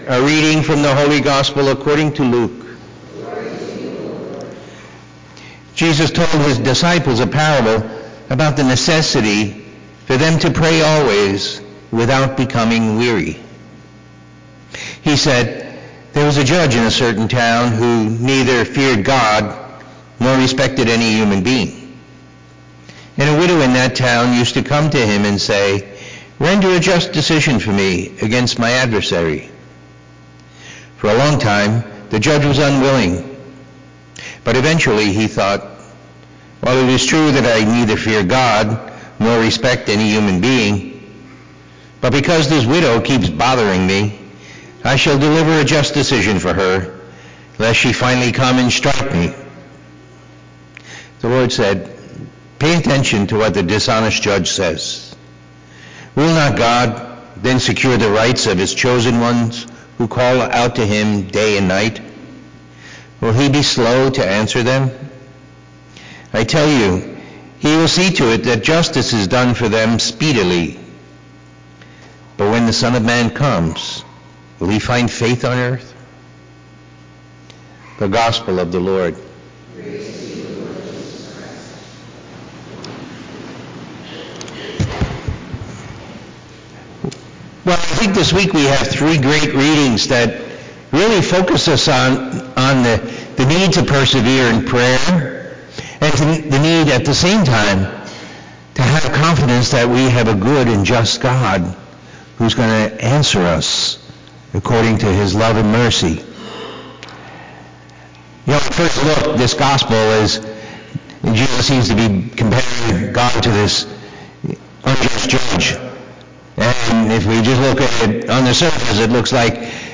Listen to the homily from the Sunday Mass and meditate on the Word of God.